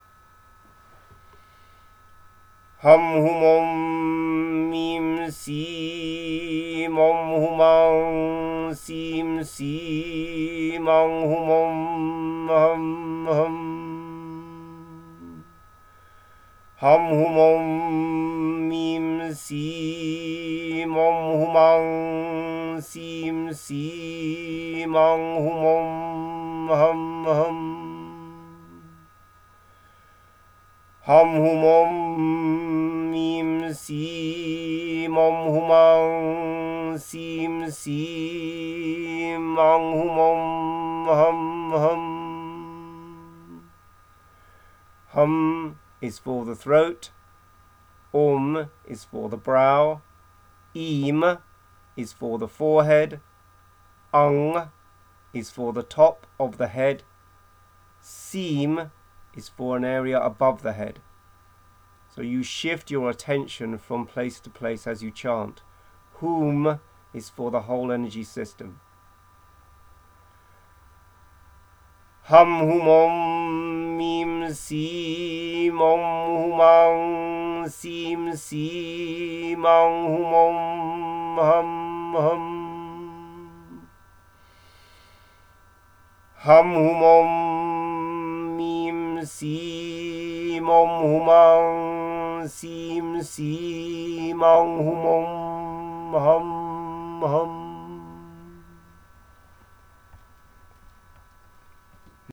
The first download entitled Unexpected Gift is a seed sound combination which when CHANTED should create subtle waves in your energy system.
Phonetically more like: Hum hoom om eem seem, om hoom ung seem seem, ung hoom om hum hum.